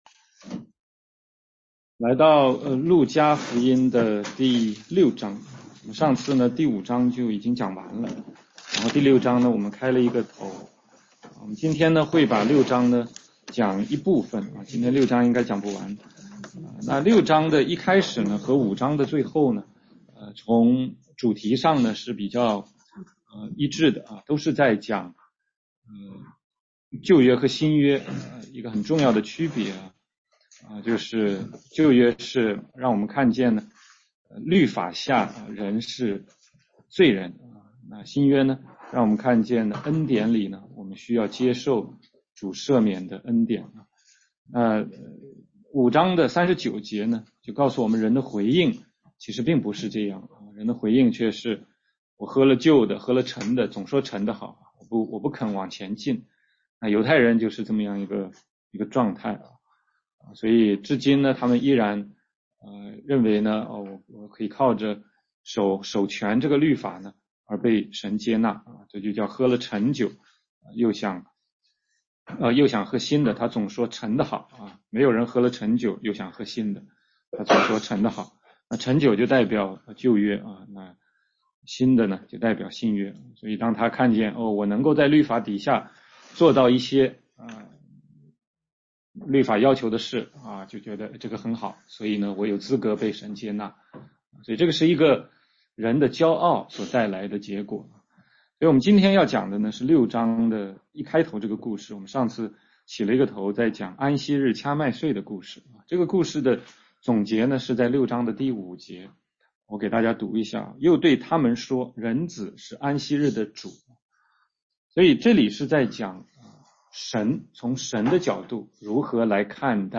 16街讲道录音 - 路加福音6章6-16节：人子是安息日的主
全中文查经